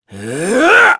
Crow-Vox_Casting2.wav